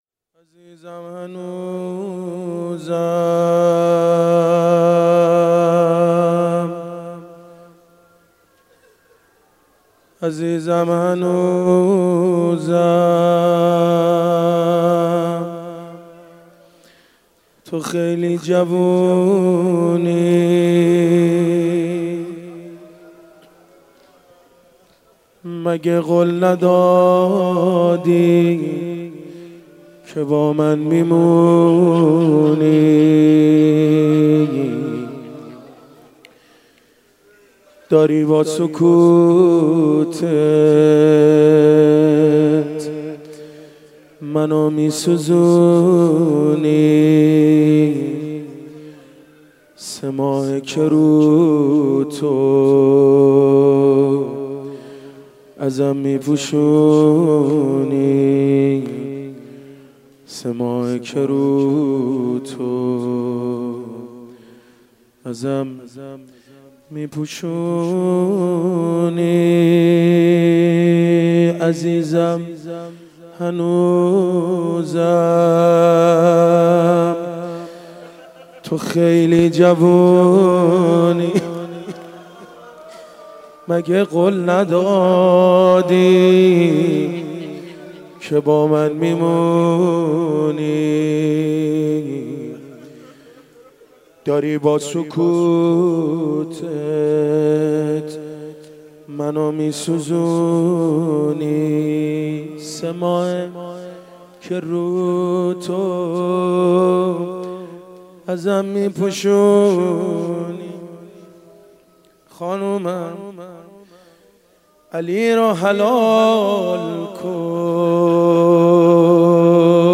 روضه مداحی